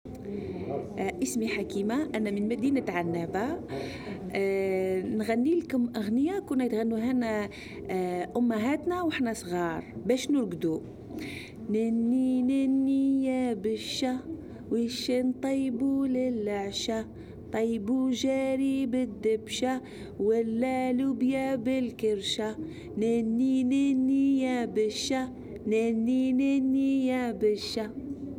berceuse algérienne en arabe